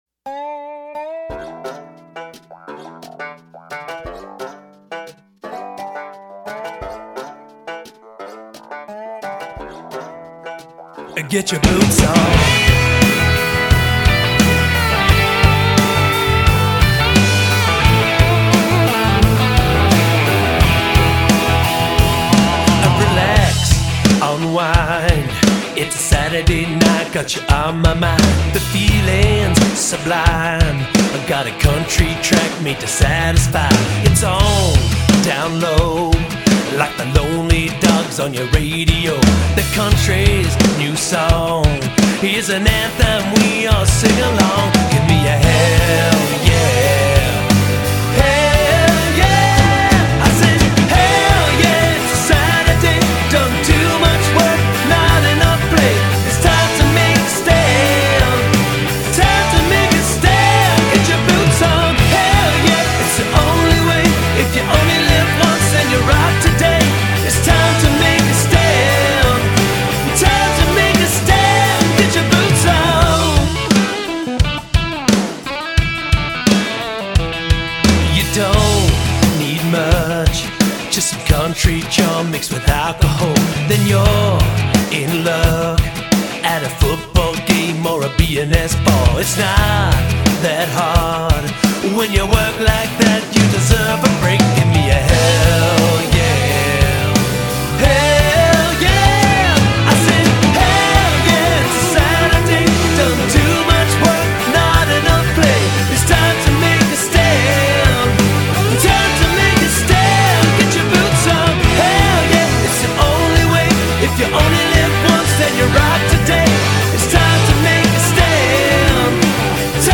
party anthem
Modern Country Rock